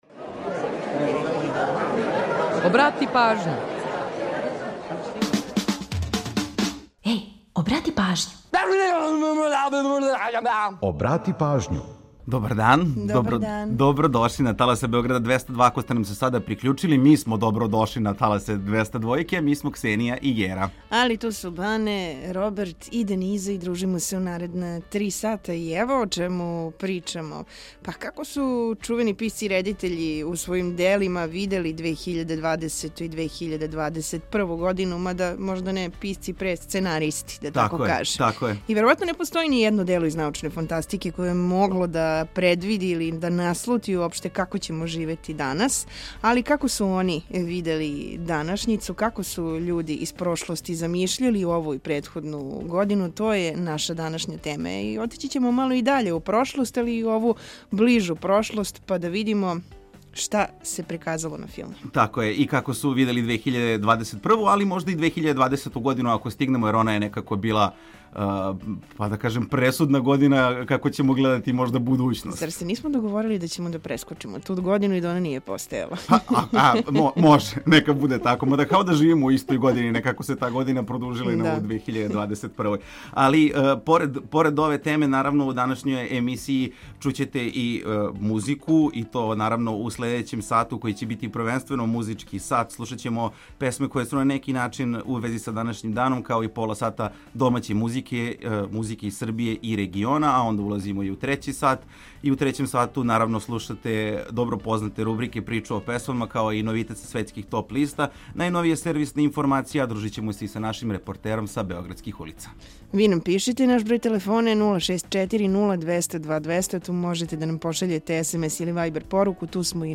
У данашњој емисији поред различитих занимљивости слушаћете и датумске песме које нас подсећају на рођендане музичара, као и годишњице објављивања албума, синглова и других значајних догађаја из историје попа и рокенрола. Ту је и пола сата резервисано само за музику из Србије и региона, а упућујемо вас и на нумере које су актуелне.